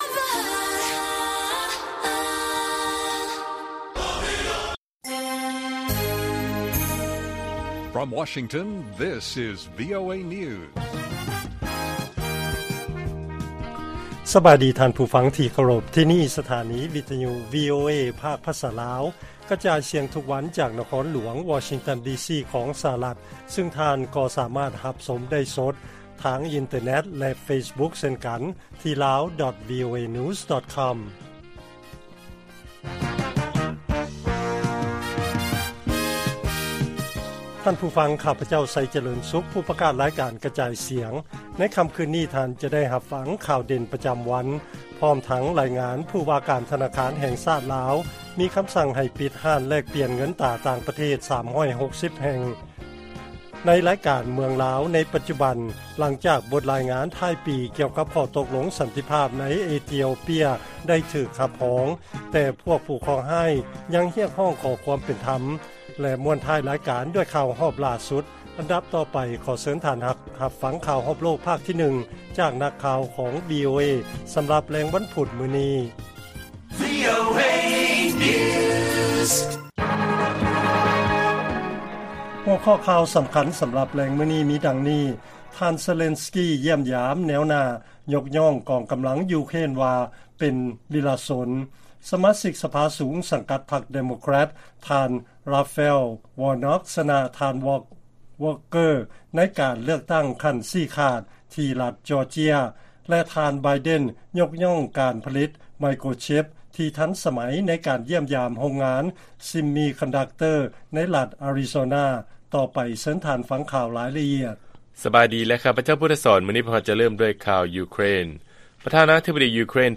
ລາຍການກະຈາຍສຽງຂອງວີໂອເອລາວ: ປັກກິ່ງແລະປະເທດເຂດອ່າວເປີເຊຍຄາດວ່າຈະເຊັນຂໍ້ຕົກລົງຫຼາຍສິບສະບັບ